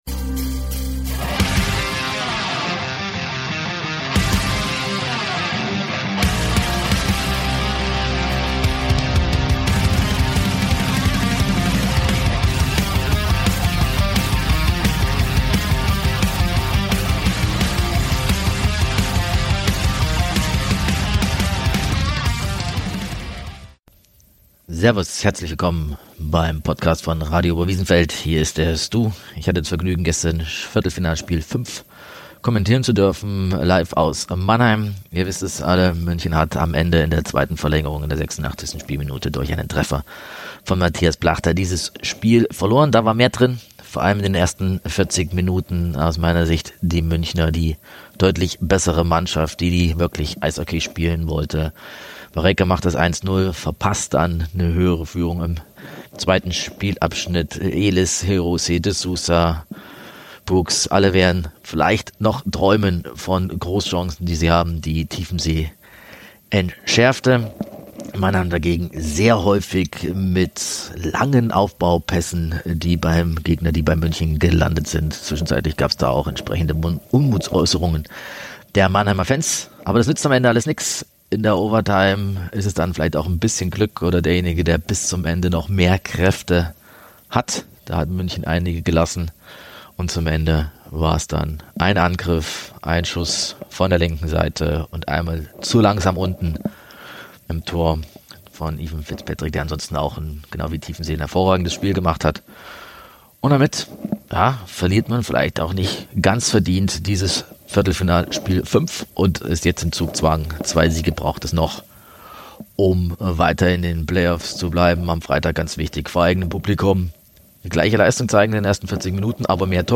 Kurze Einschätzung zur Viertelfinalniederlage in Spiel 5 und Teile des Mitschnitts er Liveübertragung aus Mannheim.